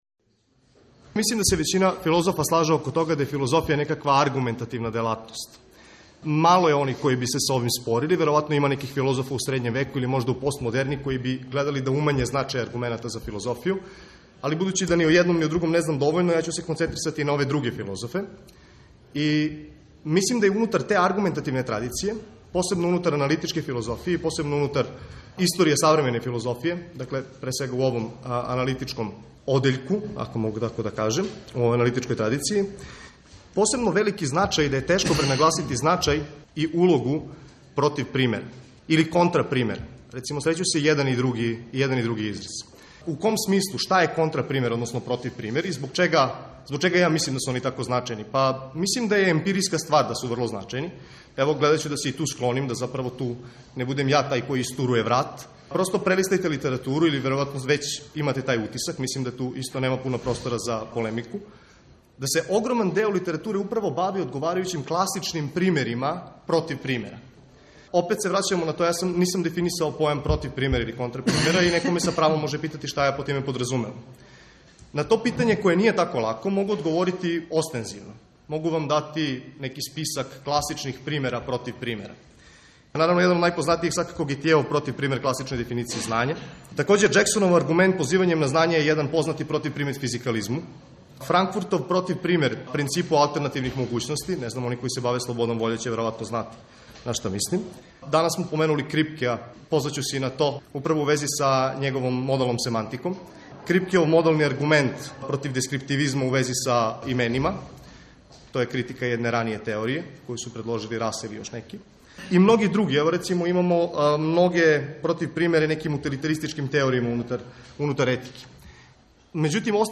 У циклусу НАУКА И САВРЕМЕНИ УНИВЕРЗИТЕТ четвртком ћемо емитовати снимке са истоименог научног скупа, који је одржан 11. и 12. новембра на Универзитету у Нишу.
Научни скупови